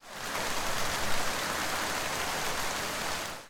rain1.ogg